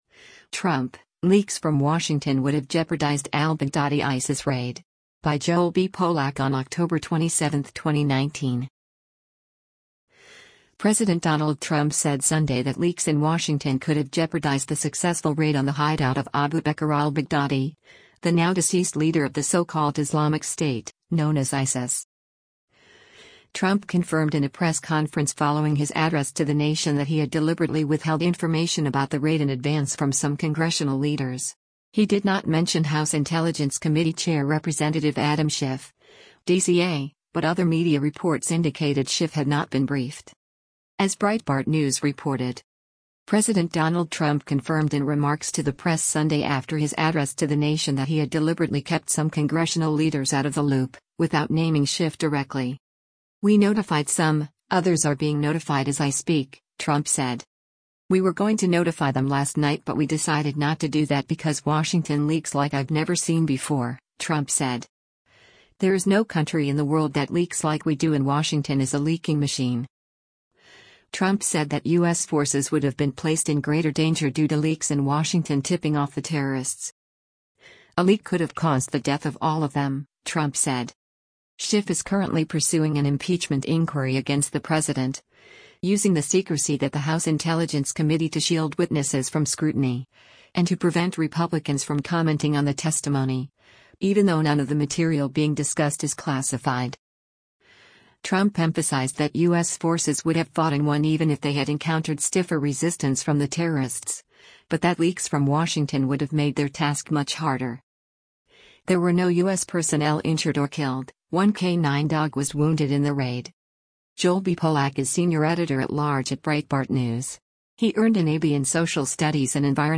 Trump confirmed in a press conference following his address to the nation that he had deliberately withheld information about the raid in advance from some congressional leaders.